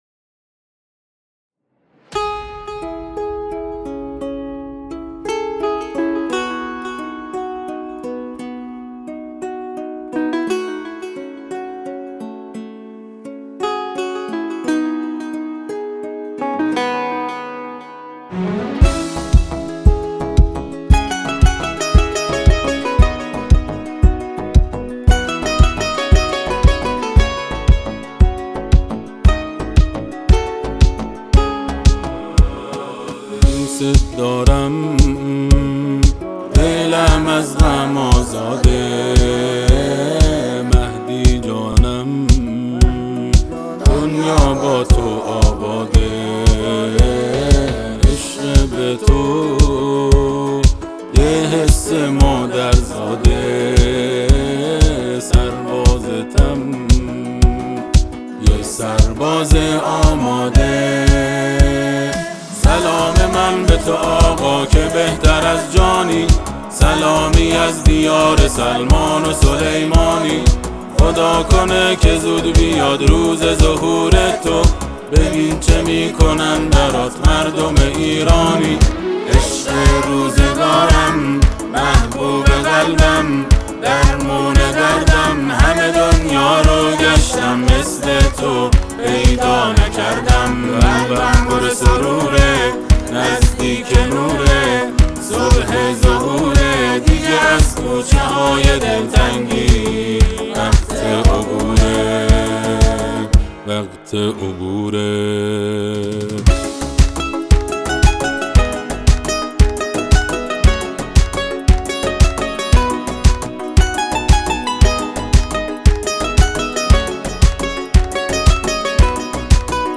نواهای آیینی